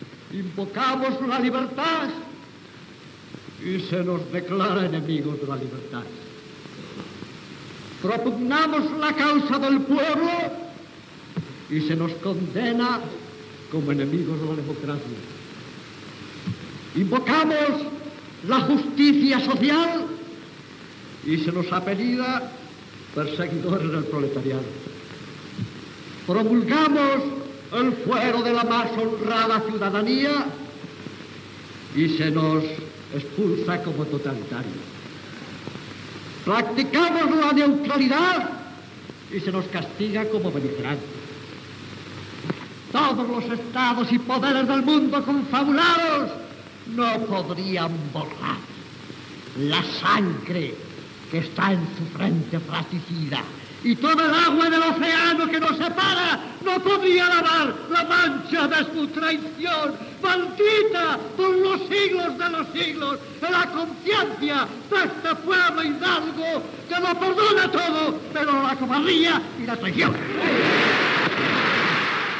Discurs d'Esteban Bilbao, president de las Cortes Españolas, criticant l'aïllament d'Espanya per part dels països guanyadors de la II Guerra Mundial.
Pronunciat el dia de la constitució de la segona etapa legislativa de les Cortes Españolas en el període regit pel general Franco.
Fragment extret del NO-DO (1946) publicat a RVE Play el 7 de juliol de 2015.